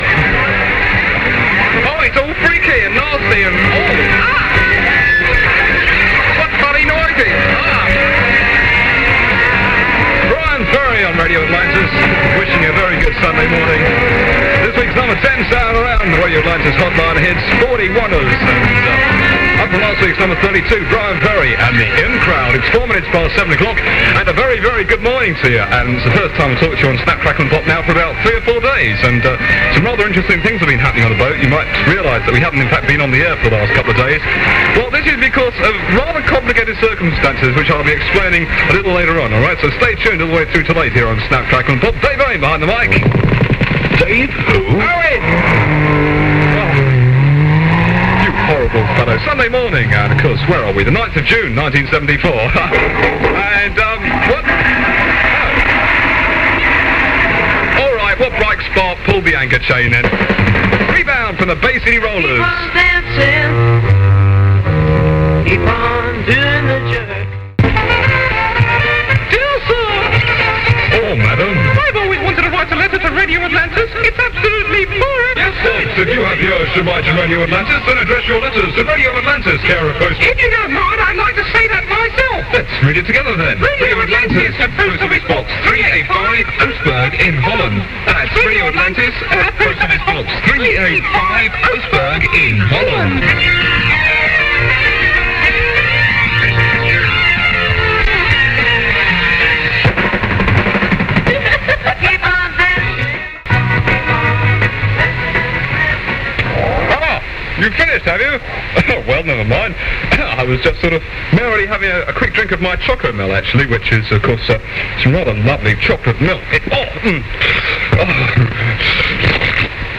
Many of these tapes are studio recordings and hopefully better quality than you will have heard before.
Radio Atlantis was heavily influenced by the offshore stations of the previous decade, with plenty of jingles and an upbeat fun presentation.